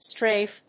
/stréɪf(米国英語), strάːf(英国英語)/